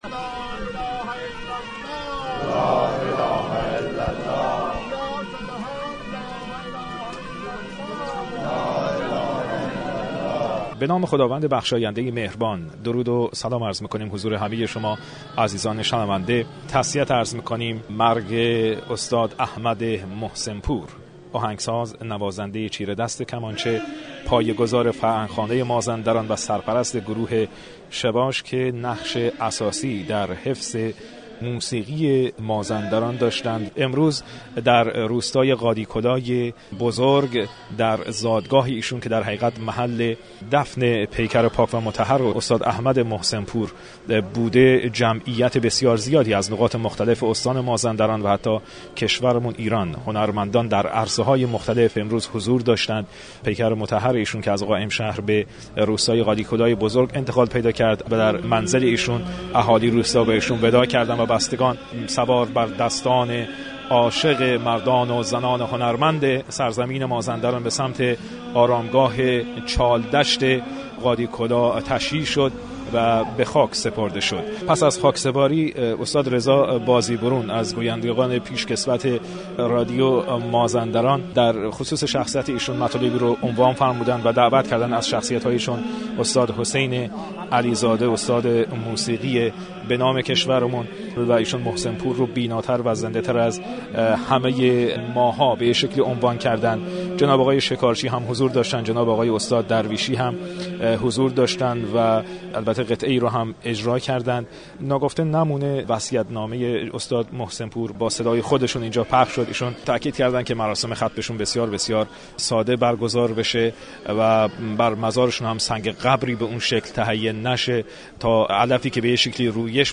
پیکر استاد احمد محسن پور -موسیقیدان بزرگ مازندرانی- در زادگاهش آرام گرفت. (گزارش+ گزارش رادیو مازندران از مراسم خاکسپاری+عکس)